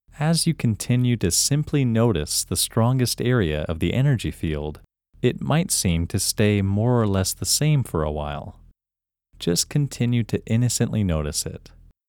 IN – First Way – English Male 11
IN-1-English-Male-11.mp3